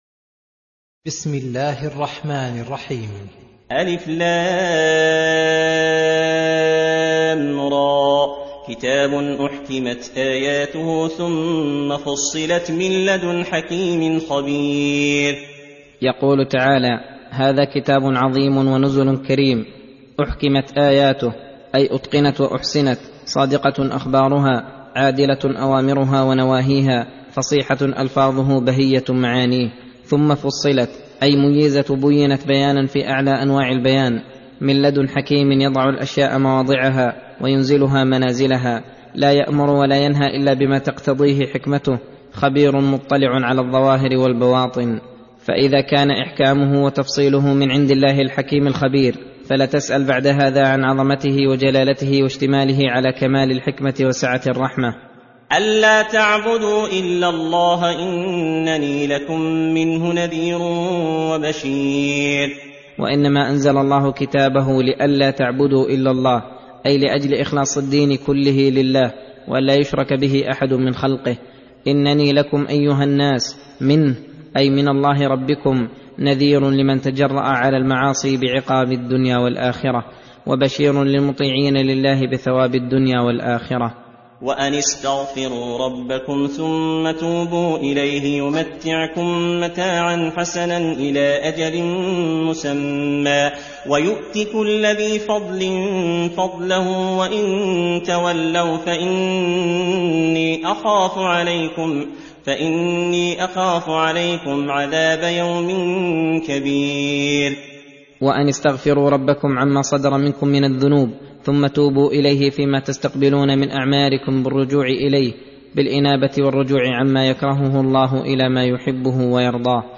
درس (31) : تفسير سورة هود : (1 - 19)